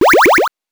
powerup_20.wav